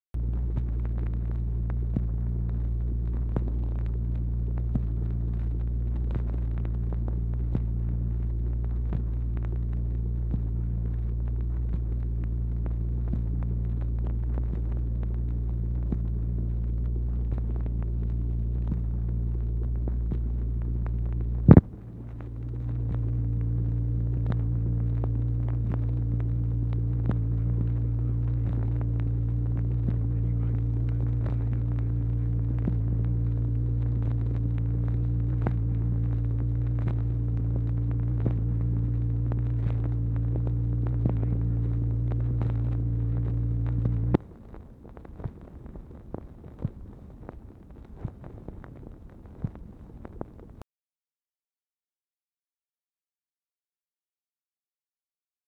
OFFICE CONVERSATION, January 1, 1964
Secret White House Tapes | Lyndon B. Johnson Presidency